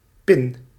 Ääntäminen
Synonyymit stift spie luns pen bout tap plug Ääntäminen Tuntematon aksentti: IPA: /pɪn/ Haettu sana löytyi näillä lähdekielillä: hollanti Käännös 1. καρφίτσα {f} (karfítsa) Suku: f .